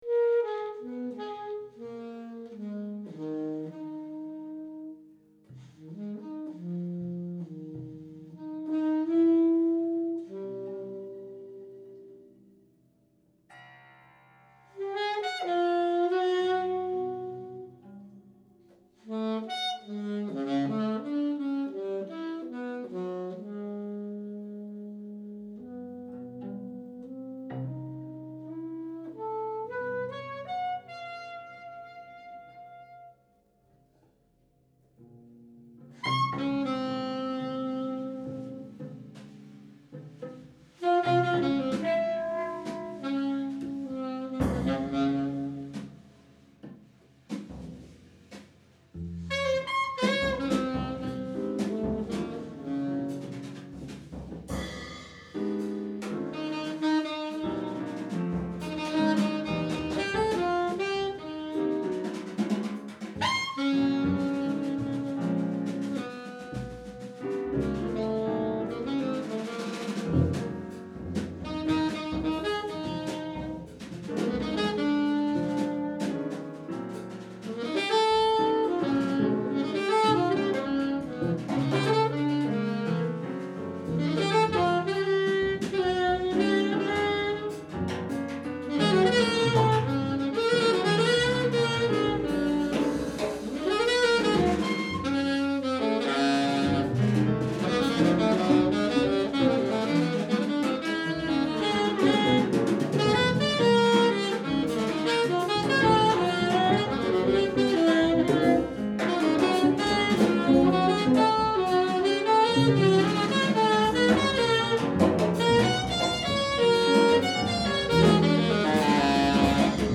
groupe de réflexion et travail qui produisit des musiques extravagantes dont voici quelques extraits :
enregistré le 24 novembre 2002 quelque part dans paris
sax alto